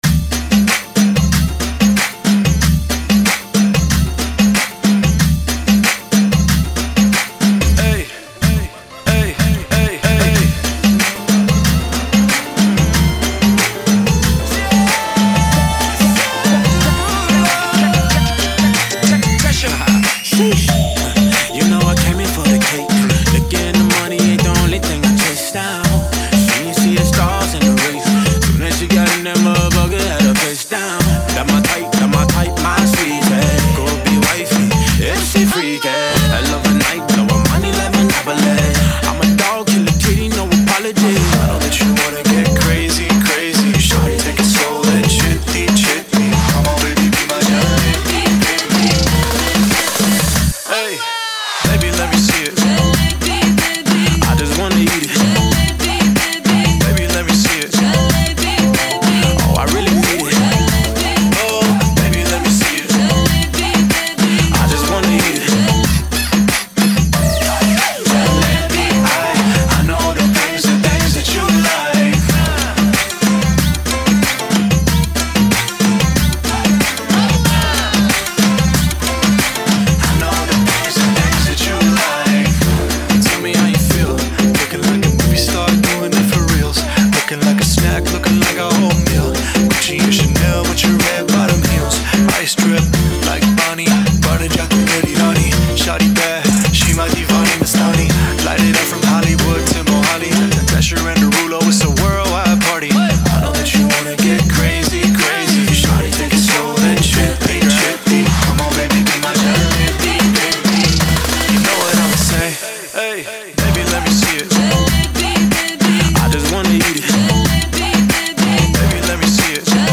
[ 93 bpm ]